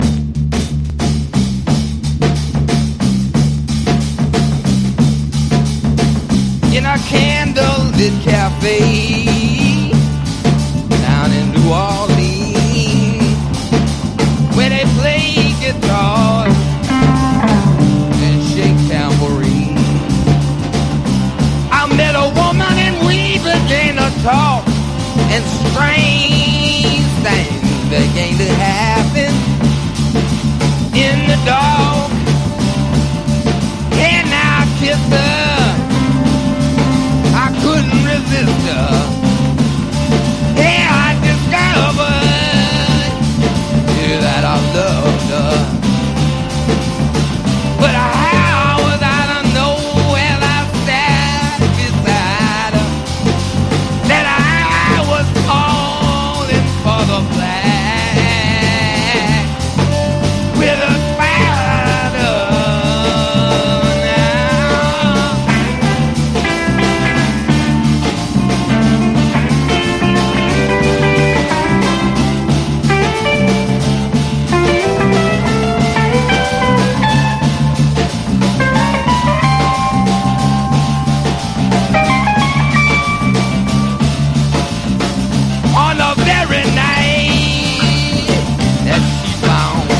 ニューオーリンズ＋サイケデリック・スワンプ！
ファンキーな